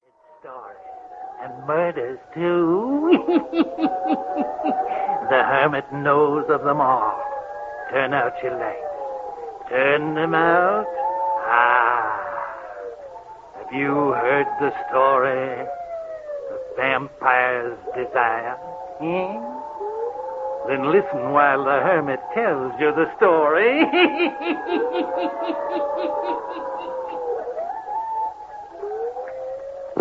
The sound effects are quite good though, and there are worse ways to kill a little time.
TheVampiresDesireRadioshow.mp3